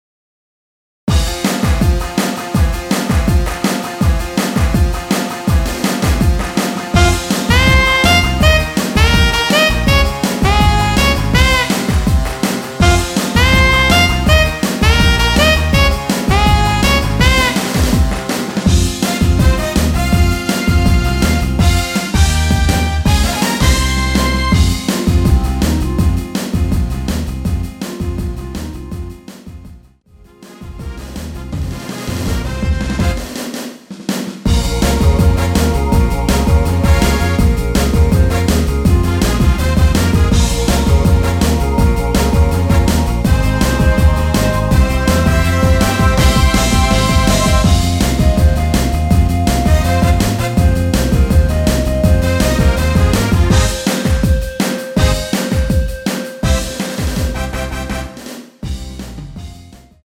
원키 멜로디 포함된 MR 입니다.(미리듣기 참조)
Fm
앞부분30초, 뒷부분30초씩 편집해서 올려 드리고 있습니다.
(멜로디 MR)은 가이드 멜로디가 포함된 MR 입니다.